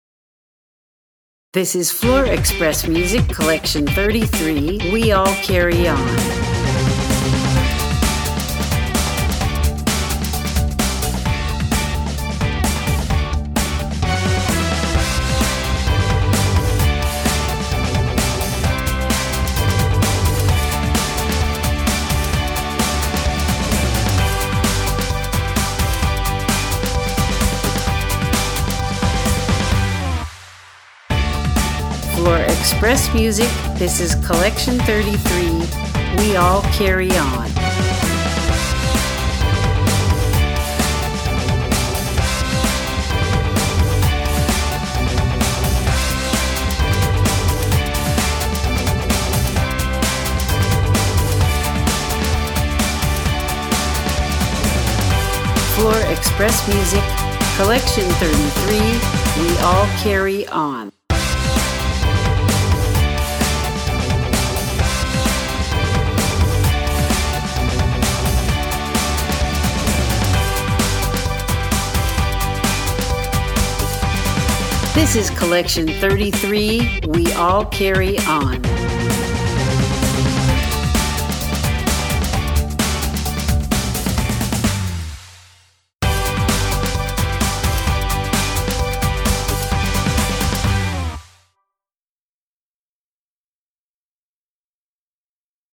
• Eclectic
• Fanfare